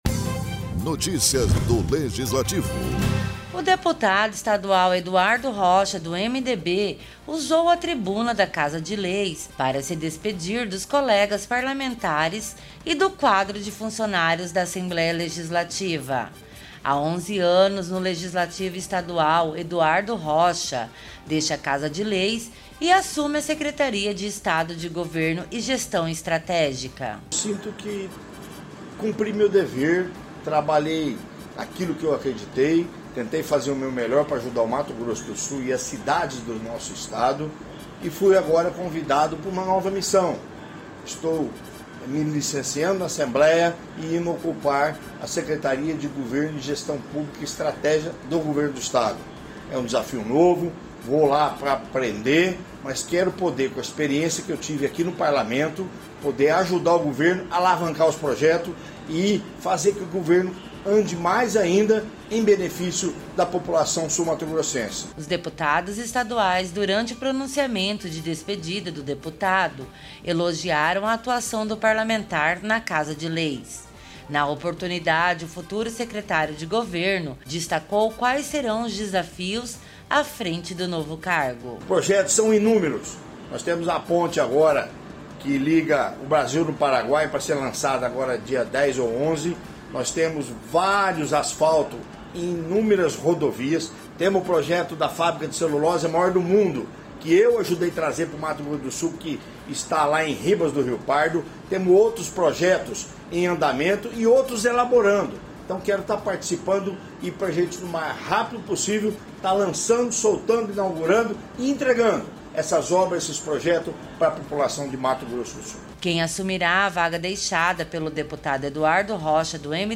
O deputado estadual Eduardo Rocha, do MDB se despediu dos colegas parlamentares e do quadro de funcionário do legisltivo, na tribuna da Assembleia Legislativa de Mato Grosso do Sul, após 11 anos de mandato. O parlamentar assume a Secretaria de Estado de Governo e Gestão Estratégica.